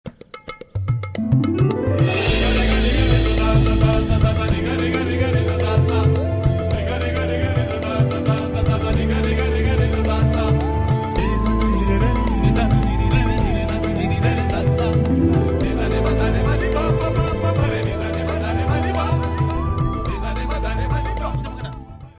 Tamil love melodies